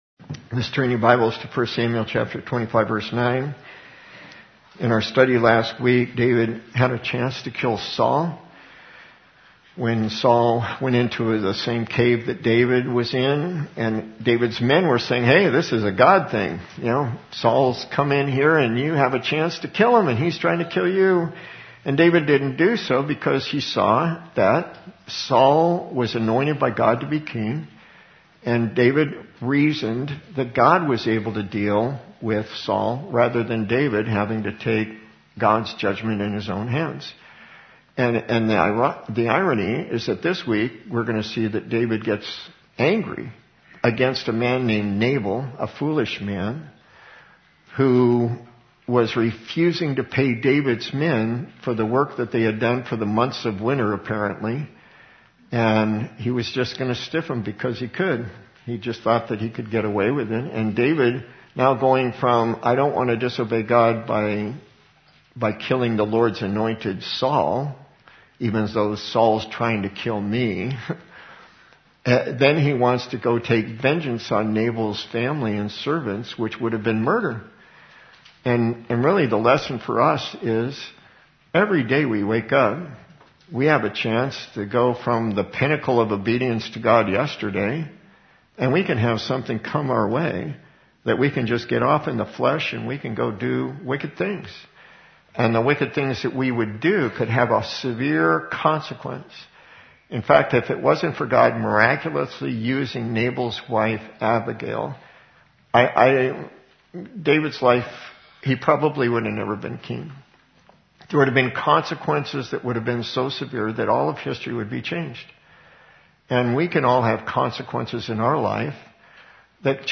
Audio File Includes Communion Learning that David is on his way to avenge her husband’s rebuff, Abigail hastens to meet him and pleads for mercy.